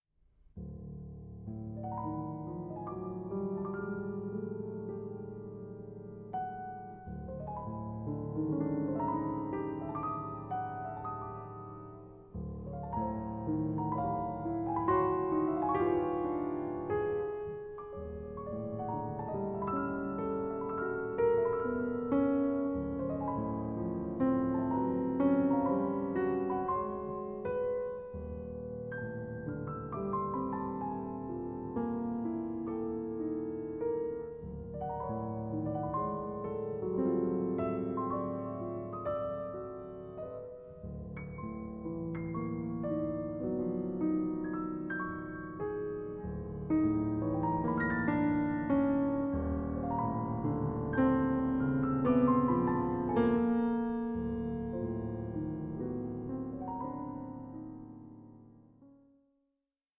PIANISTIC PLEA FOR THE POWER OF MUSIC